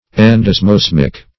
Endosmosmic \En`dos*mos"mic\, a.